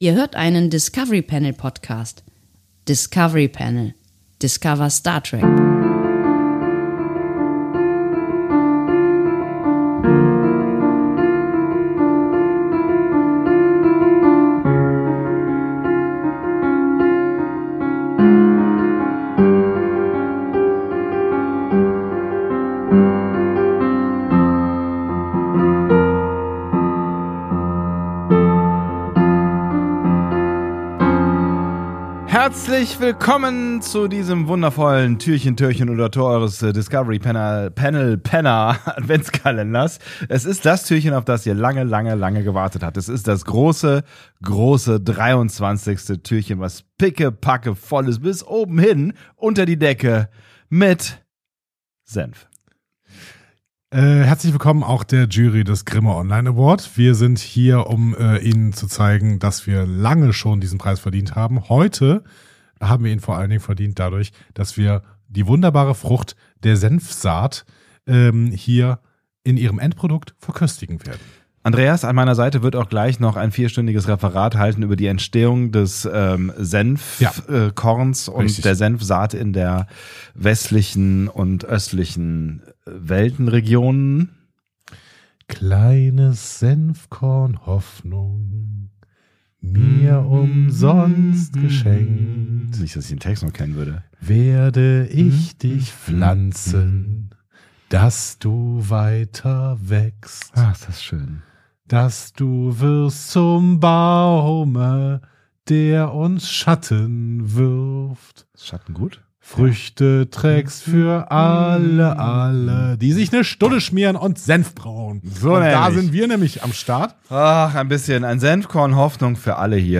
Hinweis: Diese Folge enthält Essgeräusche, Senfmeinungen und große Gefühle.